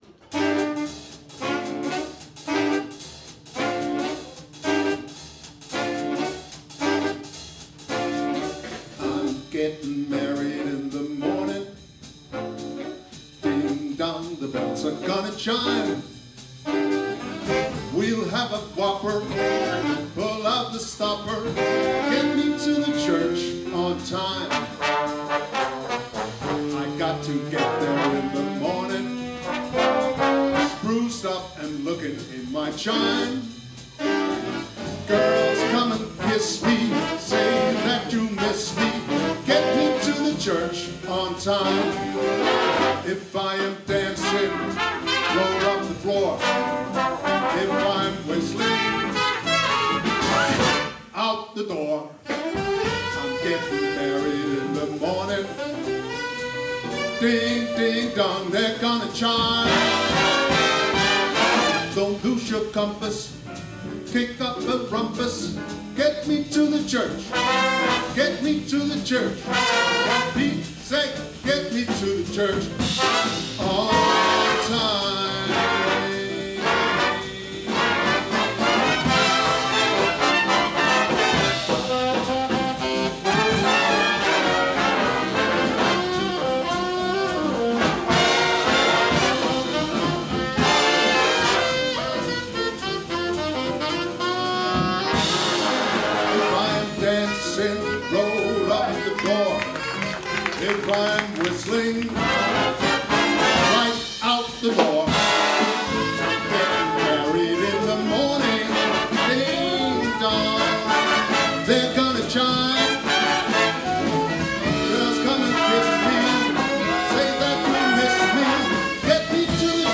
Udstyret er én digital stereo mikrofon, ikke en studieoptagelse !